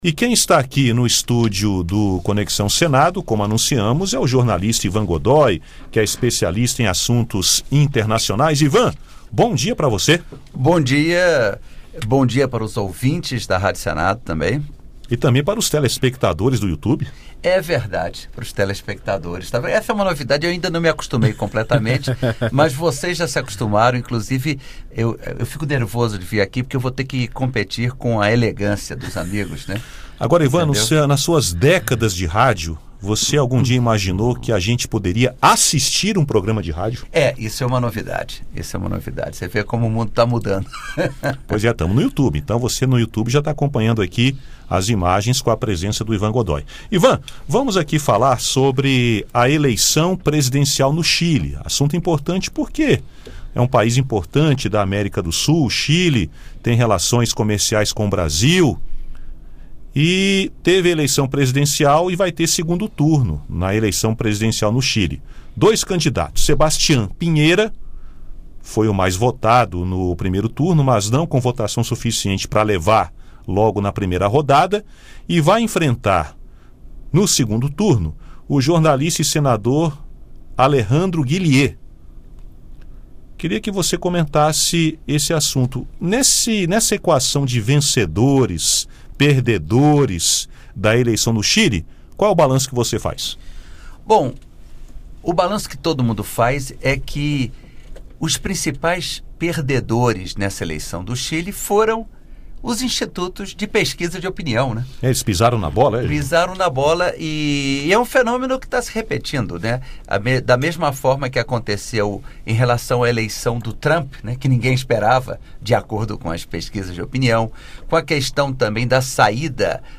Comentarista internacional